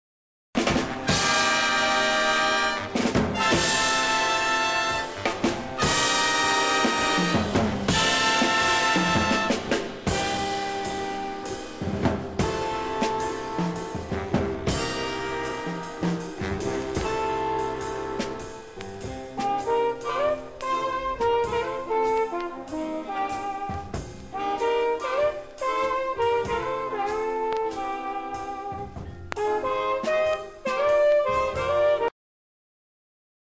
The guest musician was Clark Terry on the trumpet.
flugelhorn
Jazz vocals